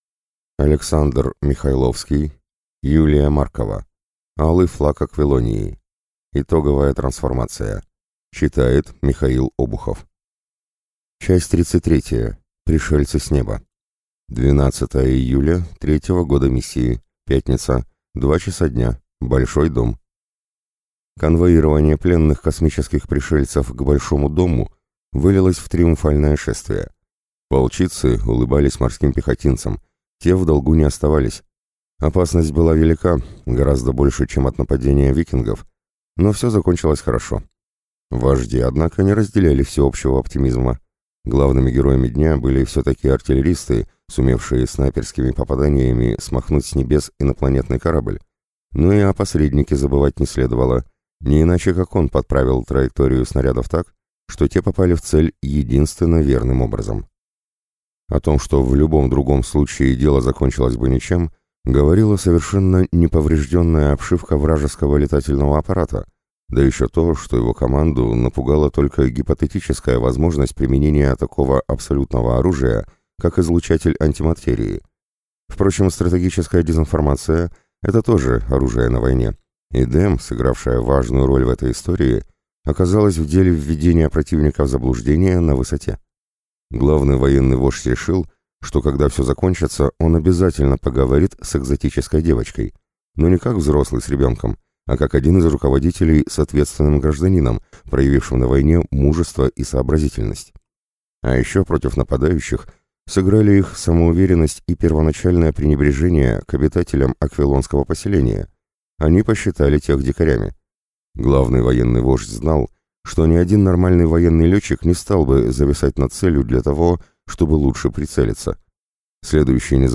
Аудиокнига Алый флаг Аквилонии. Итоговая трансформация | Библиотека аудиокниг